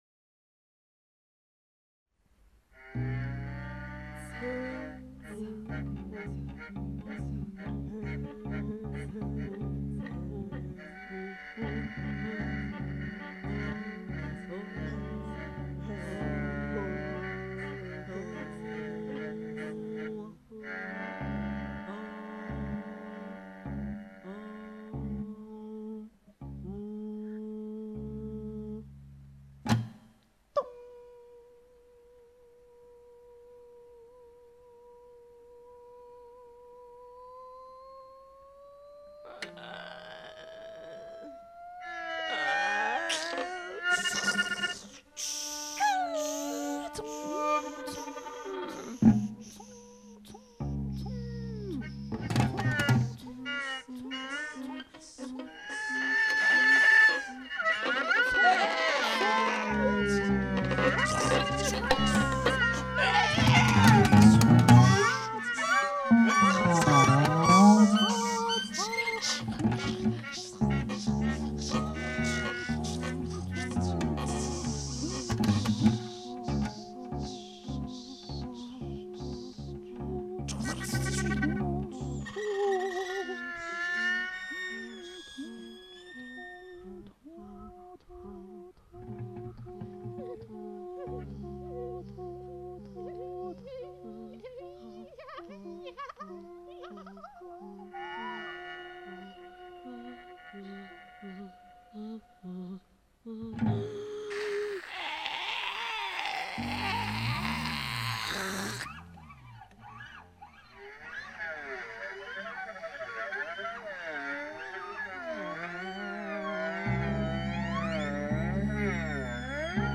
C'est un conducteur pour trois groupes d’interprètes, à savoir qu’il n’est que la notation d’une suite d’évènements situés très précisément dans le temps. Ces évènements sont répartis sur deux voies, l’une chantée, l’autre jouée avec un ensemble d’outils sonores dont le choix est laissé aux interprètes.